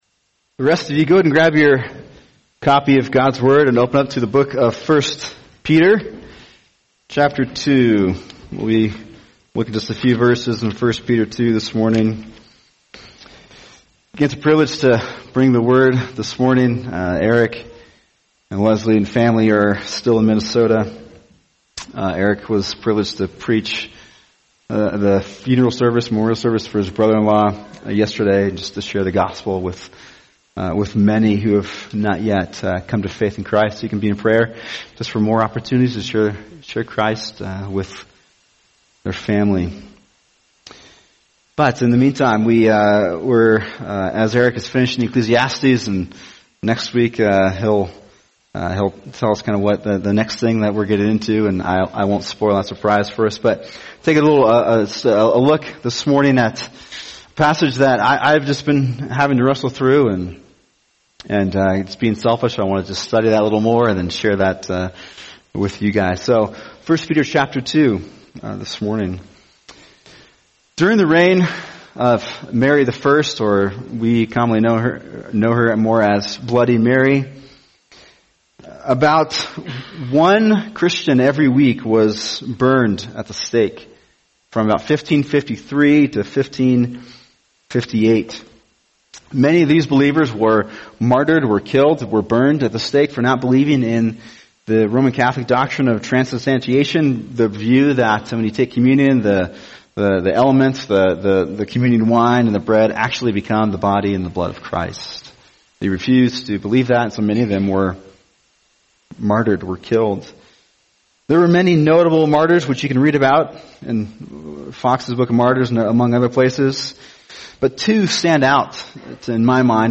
[sermon] 1 Peter 2:21-25 Suffering Without Sinning